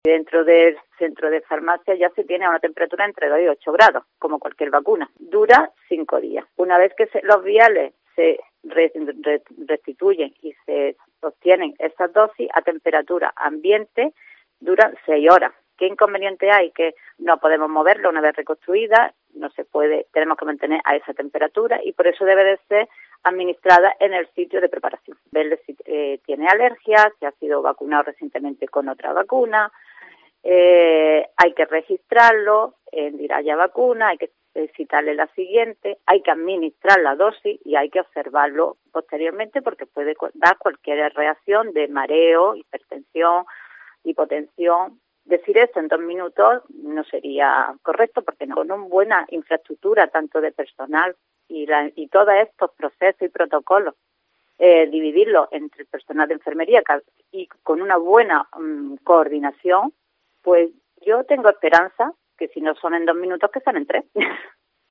Una enfermera sobre el nuevo plan de vacunación andaluz: "Hacer todo esto en dos minutos no sería correcto"